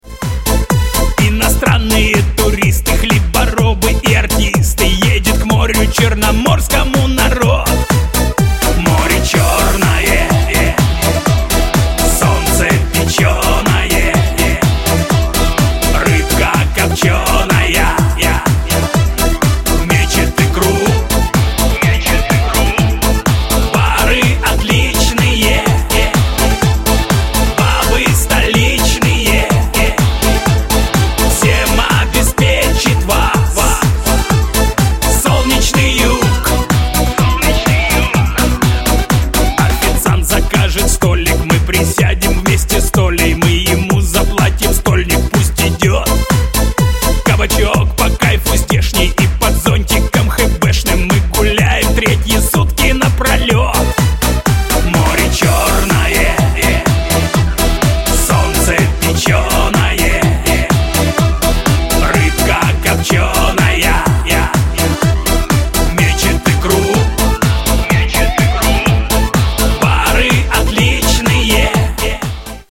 • Качество: 128, Stereo
русский шансон
блатные